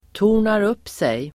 Ladda ner uttalet
Uttal: [to:r_nar'up:sej]